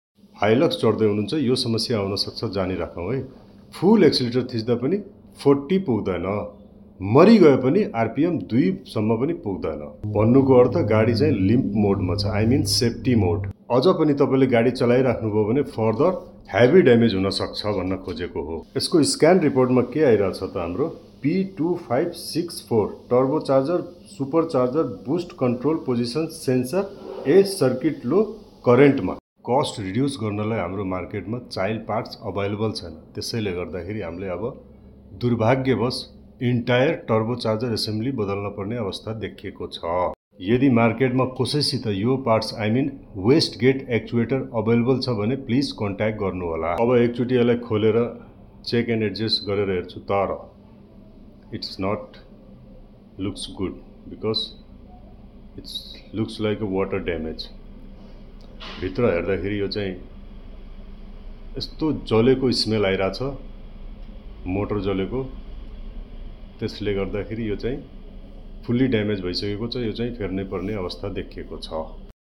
Toyota Hilux 1GD FTV 2.8ltr sound effects free download
Toyota Hilux 1GD FTV 2.8ltr engine 2015 model fault code p2564 turbocharger supercharger boost control position sensor 'A' circuit low